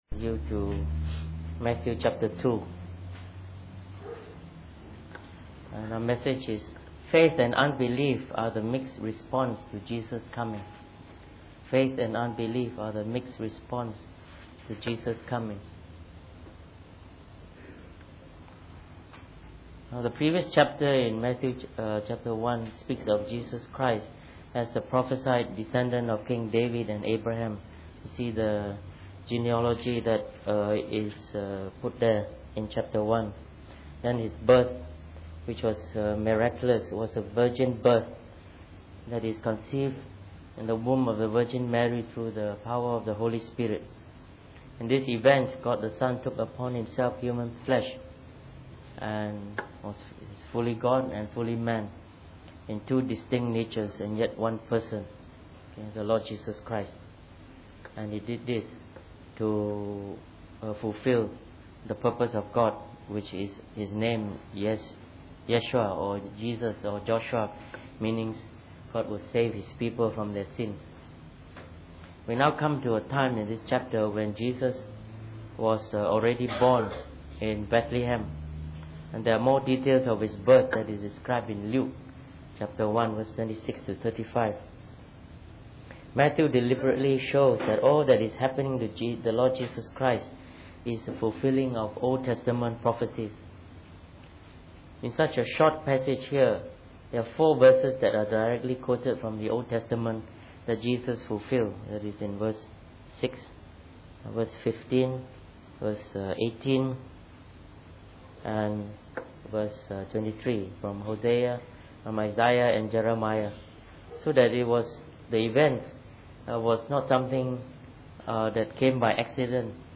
Preached on the 3rd of April 2011.